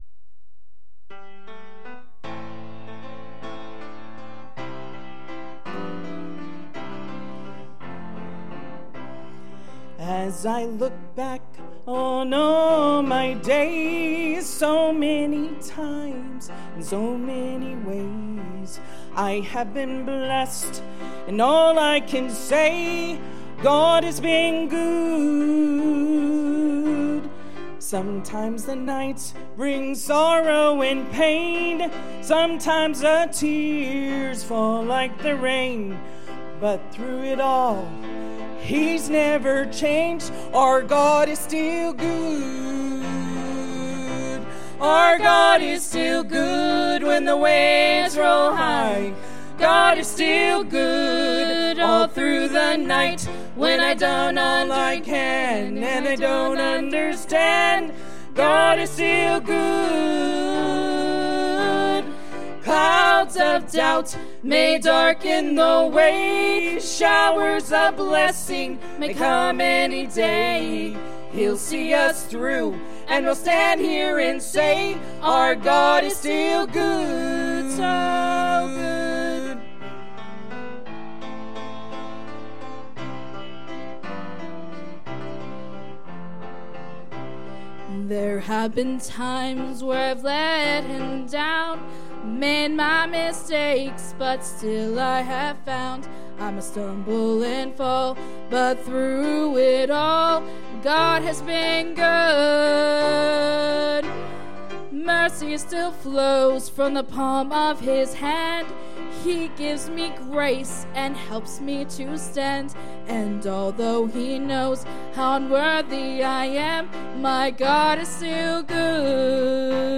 Thankful for Every Thing | Sunday AM